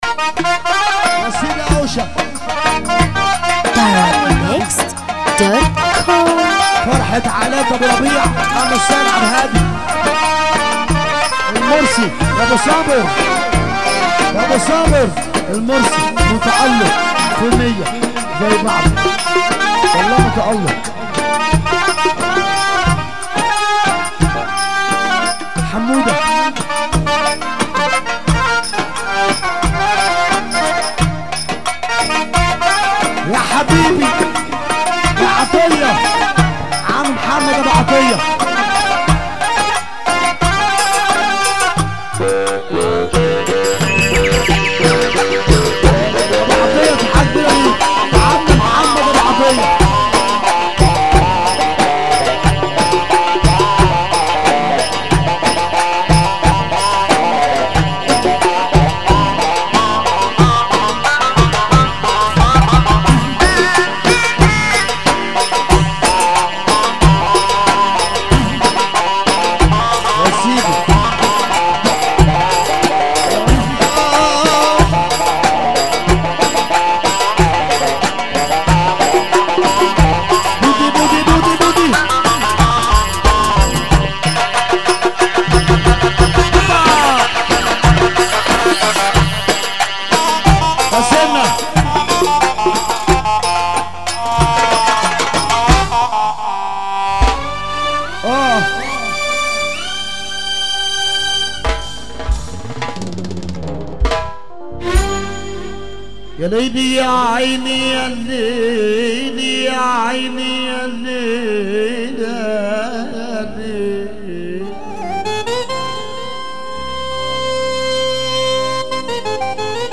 موال
حزين موت